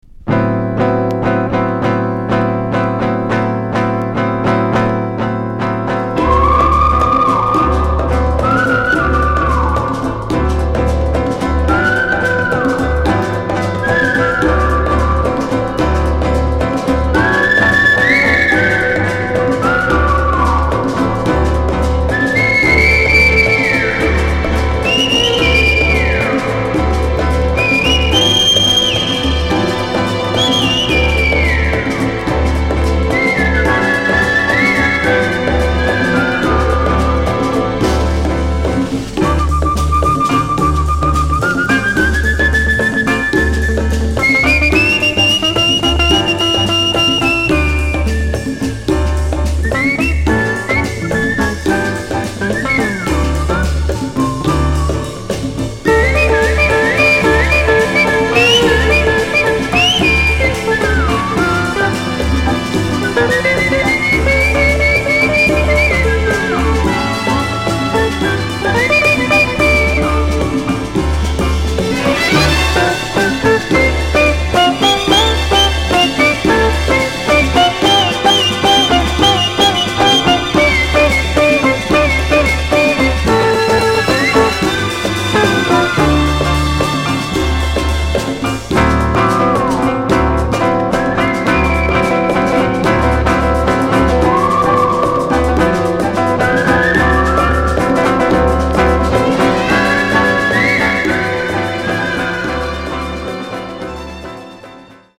1969年にスウェーデンで録音された